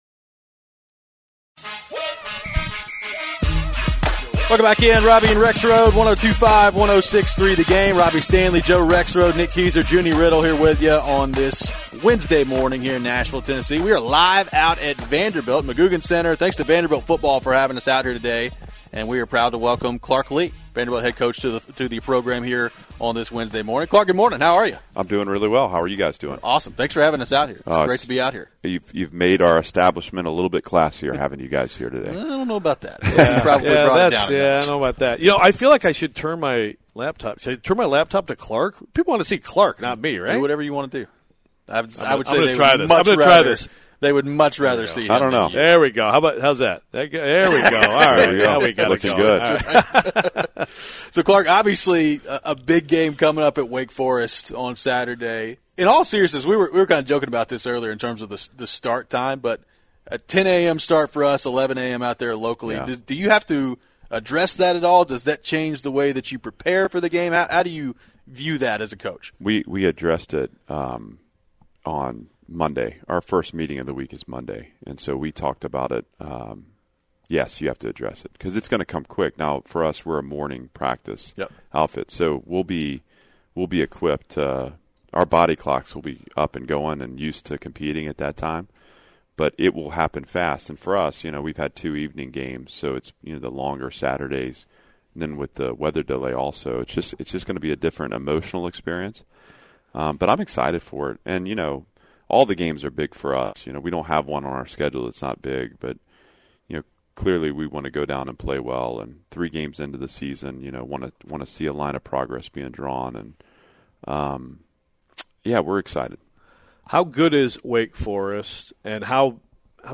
Clark Lea Interview (9-6-23)
live at the university. Coach Lea dives into the first two weeks of action and what he expects going to face Wake Forest this weekend.